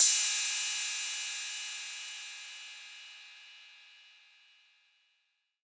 808CY_5_Orig_ST.wav